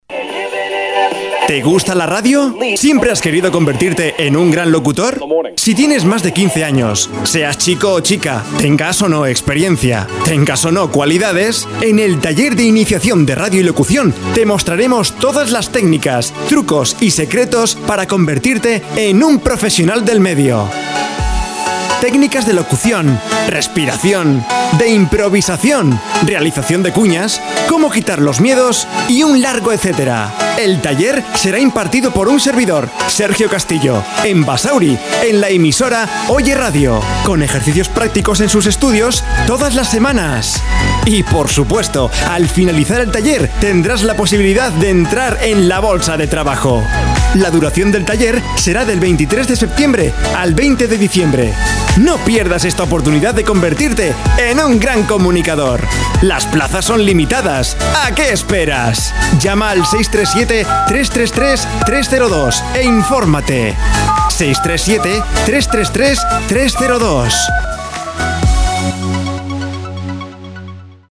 CUÑAS PUBLICITARIAS